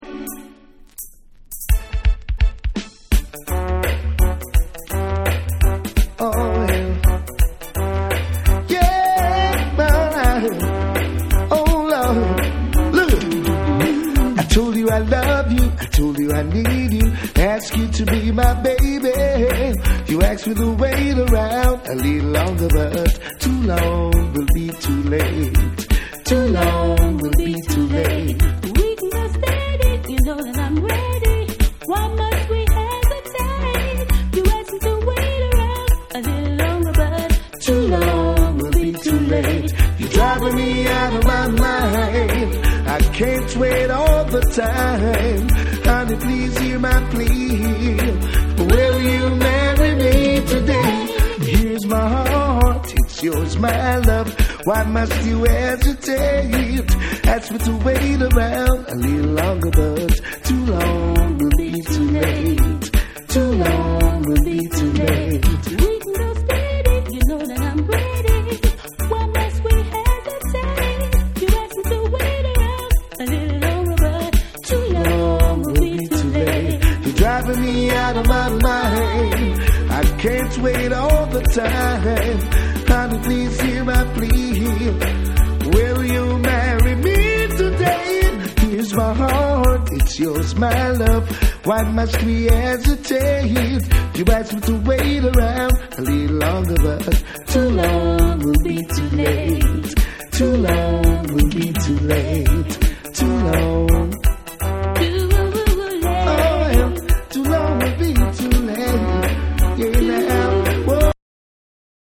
ウォーミーなホーンが効いたデジタルなリズムに抜群のコンビネーションが絡み展開する絶品ラヴァーズ・チューン！
REGGAE & DUB / LOVERS